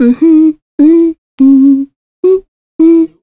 Scarlet-vox-Hum_kr.wav